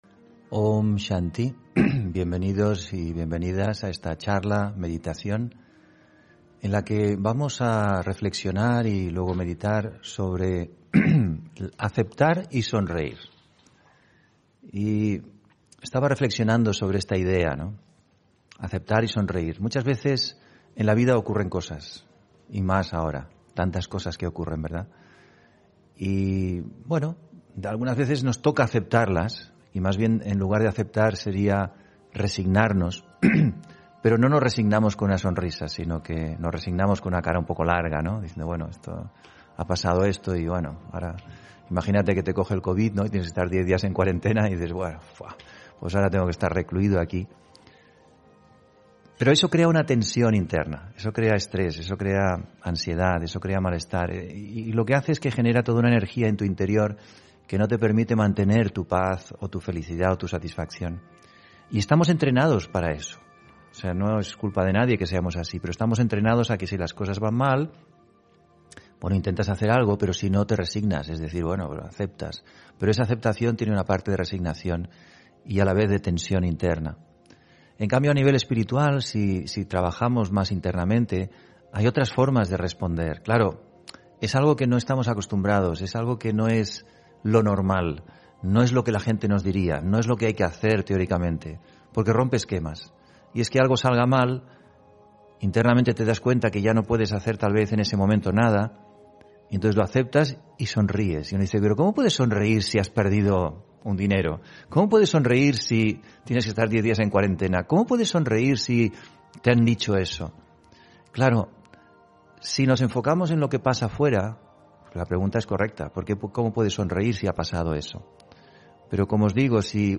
Meditación Raja Yoga y charla: Aceptar y sonreír (23 Julio 2021) On-line desde Madrid